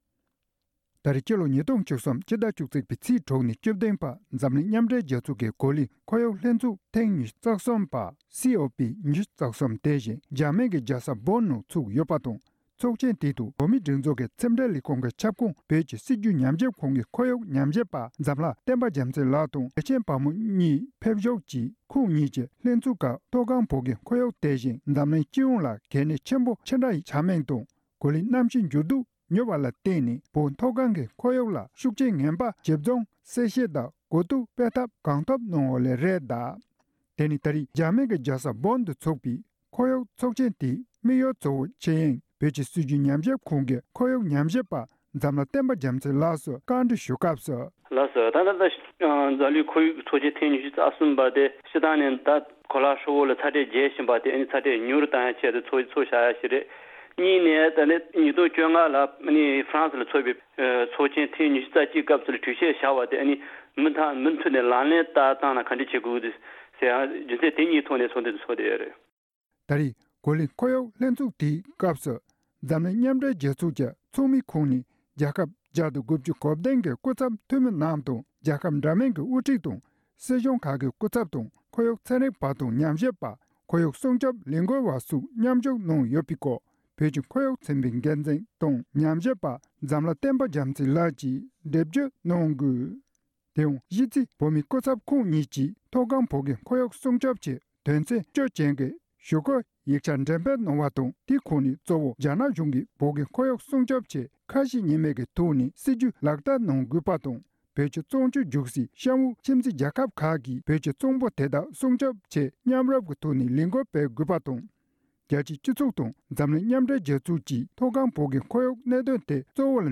༄༅༎དེ་རིང་གི་གཏམ་གླེང་ཞལ་པར་ལེ་ཚན་ནང་འཛམ་གླིང་མཉམ་འབྲེལ་རྒྱལ་ཚོགས་ཀྱི་གོ་ལའི་ཁོར་ཡུག་ཚོགས་ཆེན་ཐེངས་༢༣འདི་ཇཱར་མེ་ནི་ནང་སྐོང་ཚོགས་གནང་ཡོད་པས།